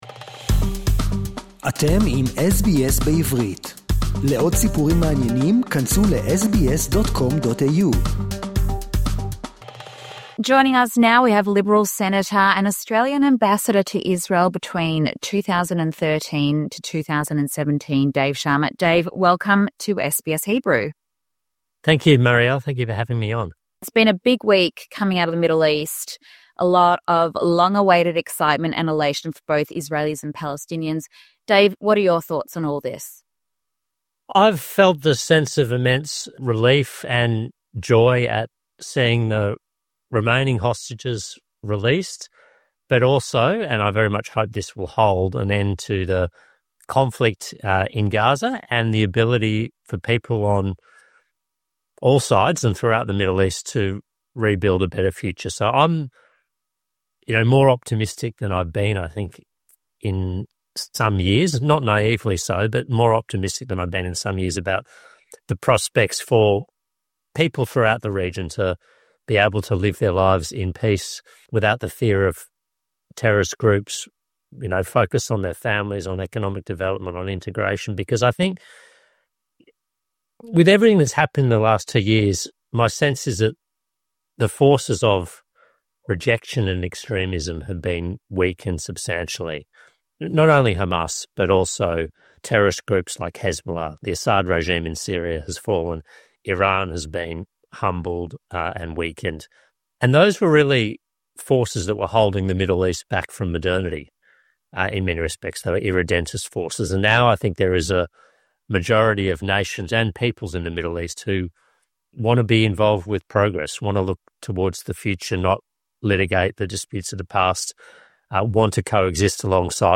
This week, we speak with Dave Sharma, current Liberal Senator and former Australian Ambassador to Israel (2013–2017).